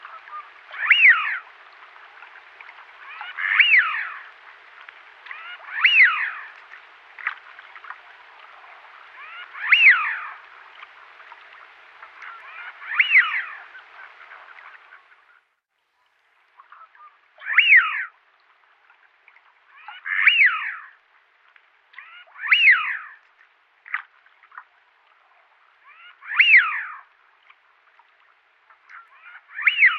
Canard siffleur en photo - Mes Zoazos
Le nom du canard siffleur vient de son cri, un sifflement mélodieux que l’on entend fréquemment le soir.
canard-siffleur.mp3